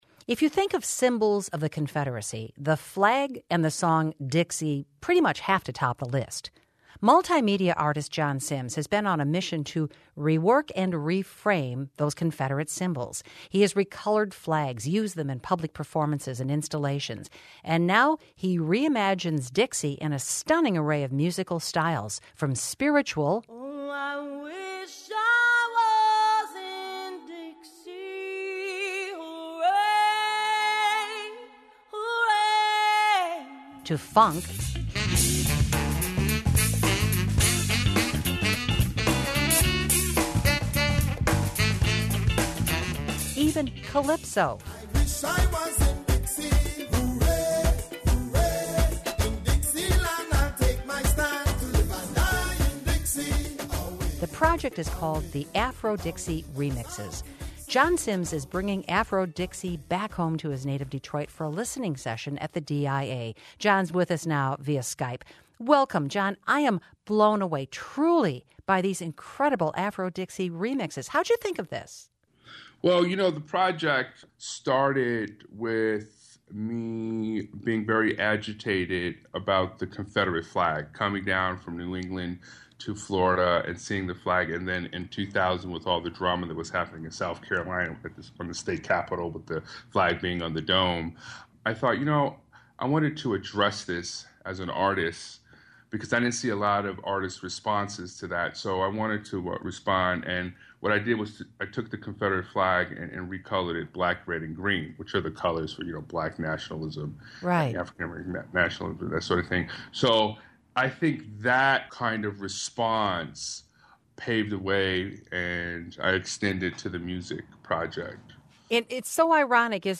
GUEST